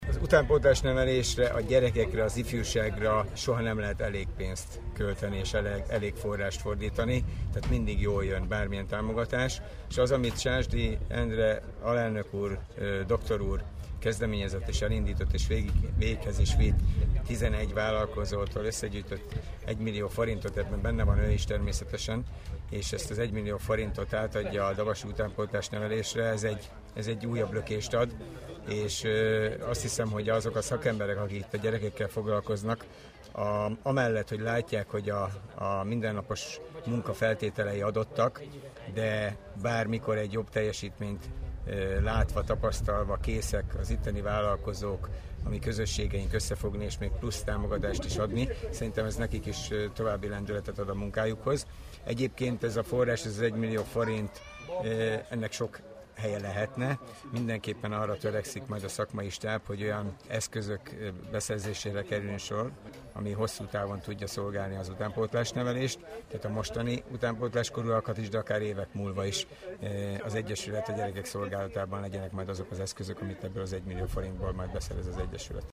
A tornán Kőszegi Zoltán Dabas Város polgármestere 1 millió forintértékben vett át támogatást, rádiónknak arról beszélt mire fogják ezt fordítani.